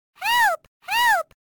Voice clip of Pauline calling for help from Mario vs. Donkey Kong: Minis March Again!.
Source Recorded from the sound test feature of Mario vs. Donkey Kong: Minis March Again! using an emulator Artist Unknown/Uncredited voice actress Image help • Image use policy • Media file guidelines Licensing [ edit ] Fair use sound clip This is a sound clip from a copyrighted work.